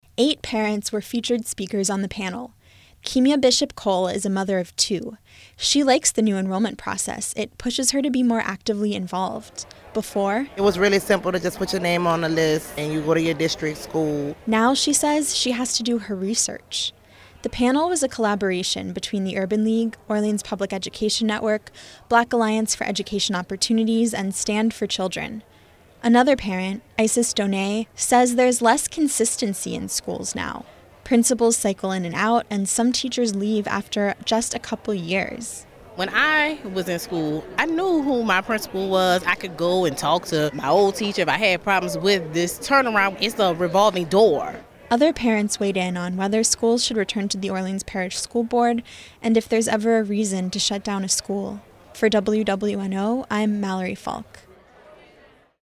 At a recent panel, parents took to the stage to reflect on the past 10 years.
Eight parents were featured speakers on the panel. They talked about enrollment, governance and accountability.